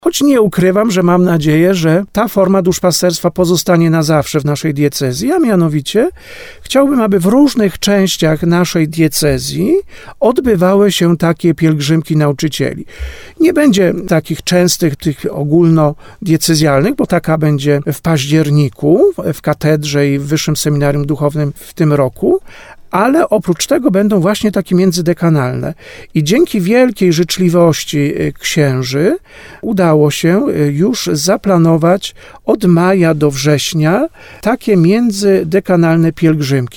Jak przyznał w rozmowie z Radiem RDN, celem jest wychodzenie naprzeciw środowiskom nauczycieli i wychowawców, szczególnie w przeżywanym teraz Roku Jubileuszowym.